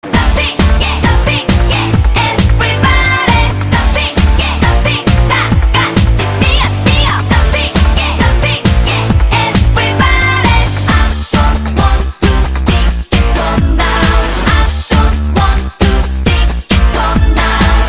• Качество: 192, Stereo
женский вокал
зажигательные
подвижные
K-Pop